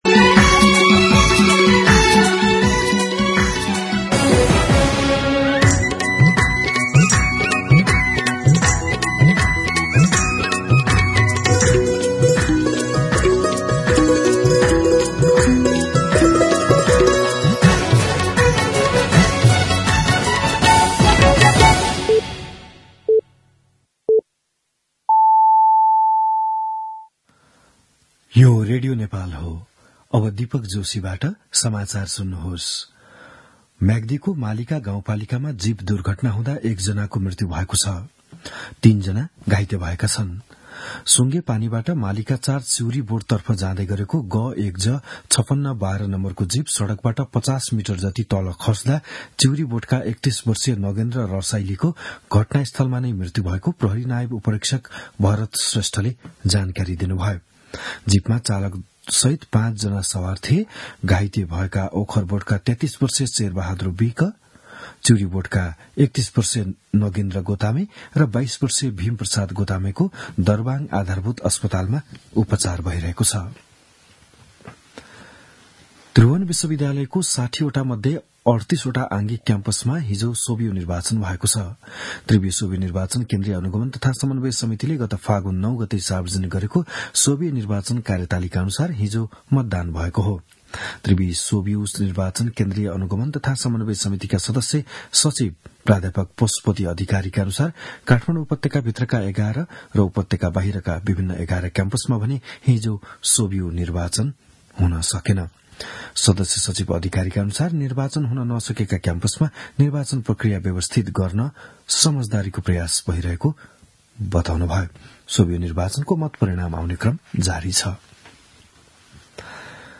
बिहान ११ बजेको नेपाली समाचार : ६ चैत , २०८१